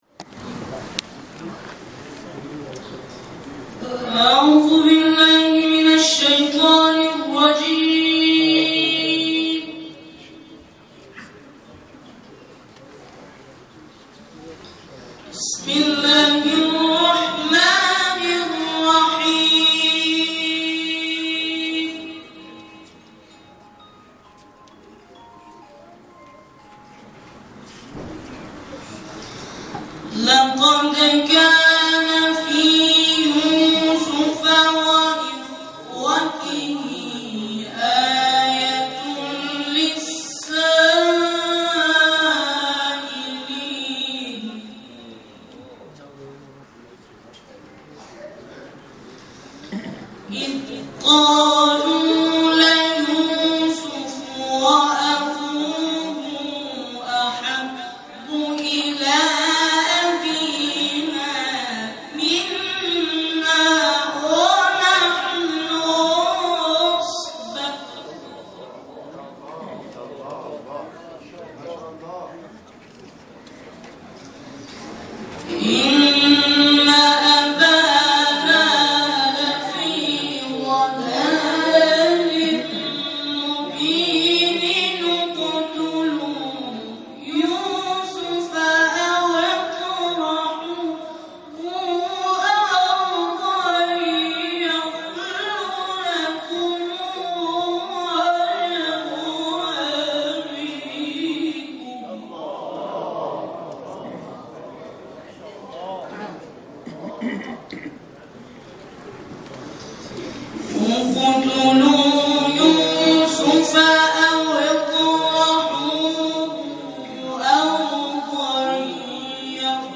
همخوانی گروه «سبیل‌الرشاد» در اختتامیه مسابقات سراج صوت - تسنیم
صوت اجرای برنامه گروه همخوانی «سبیل‌الرشاد» در مراسم اختتامیه چهارمین دوره مسابقات قرآنی سراج منتشر شد.
مشتمل بر نوجوانان قرآنی کشورمان